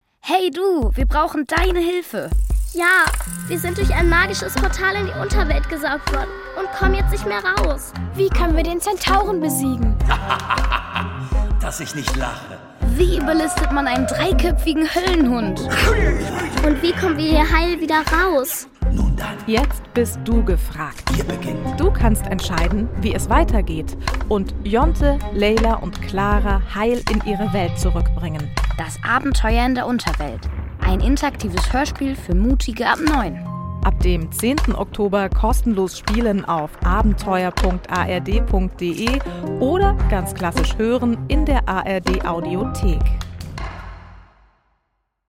Kinderhörspiel: Das Abenteuer in der Unterwelt